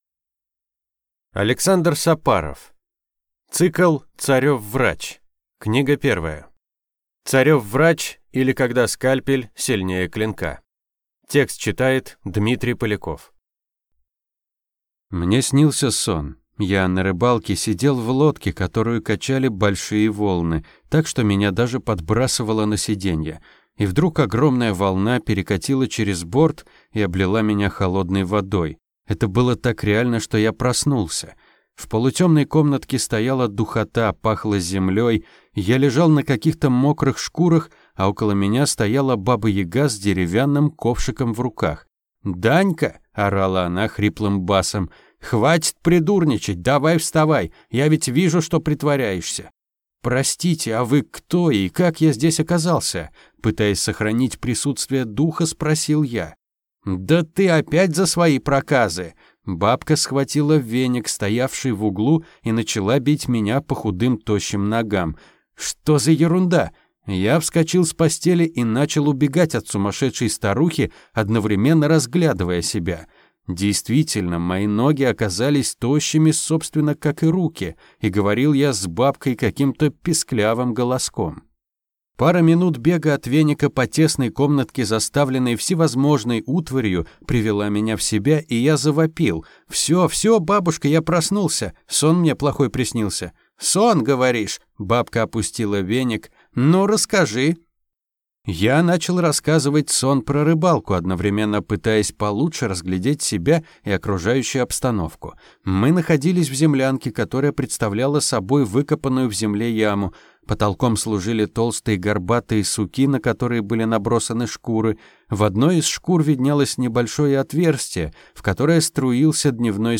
Aудиокнига Царев врач